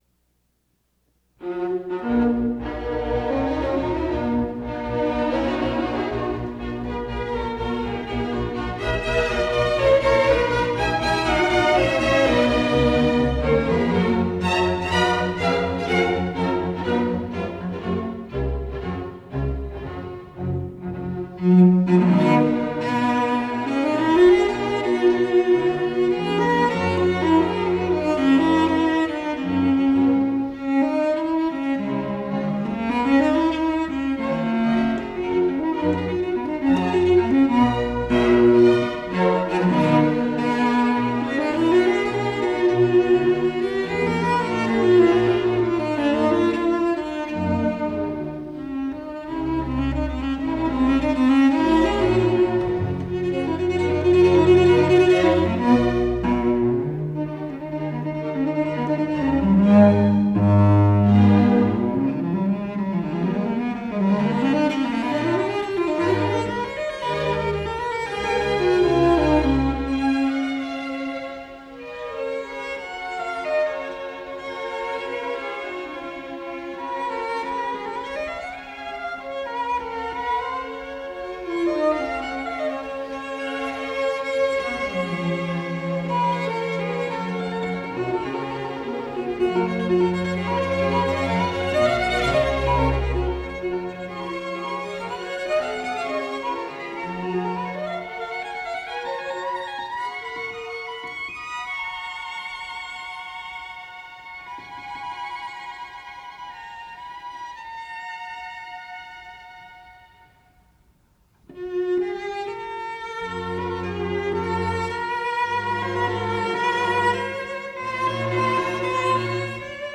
recorded in England